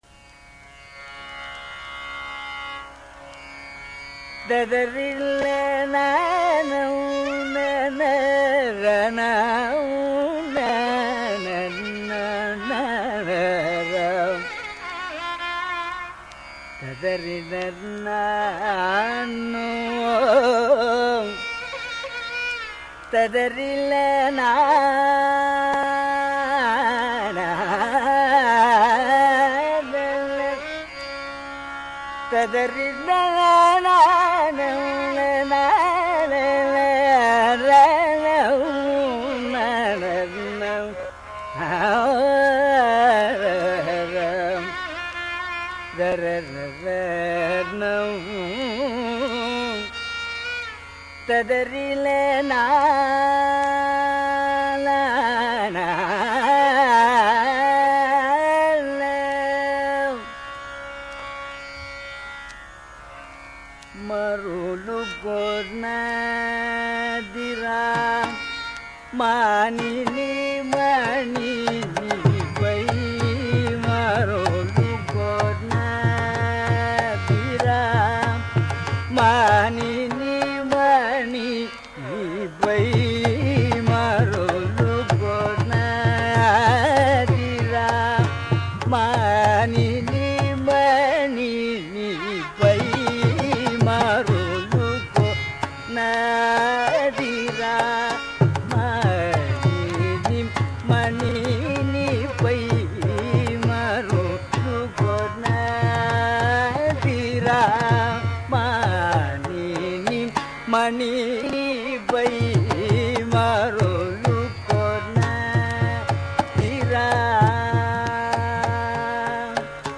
in rāga Kamās and tāla Deśādi.
mrdangam
violin
tambura
Deśādi tāḷa is a simplified 4-beat version of Ādi tāḷa.